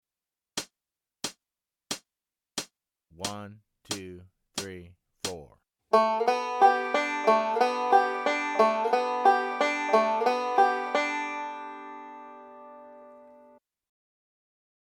Voicing: Banjo Method